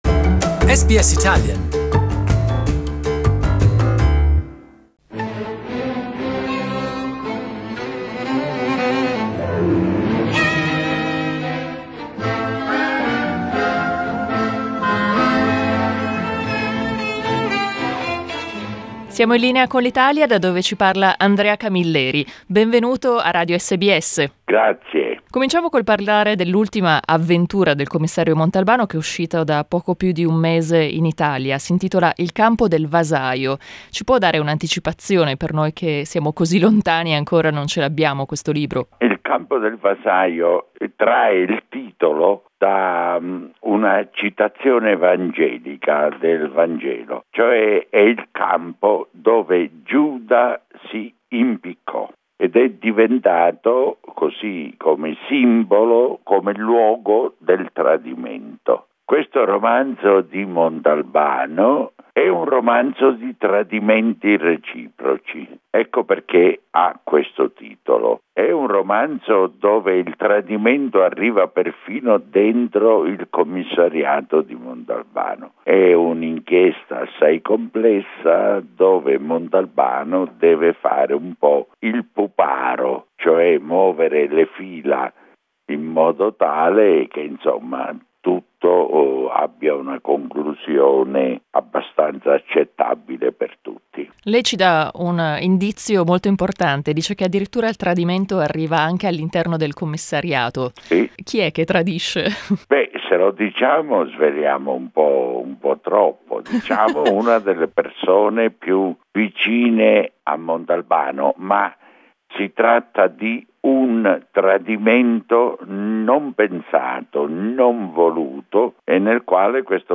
In this interview recorded in 2008, Sicilian writer Andrea Camilleri spoke to us about the books he had just published and the one he had already given to his publisher: the last book of the Montalbano series.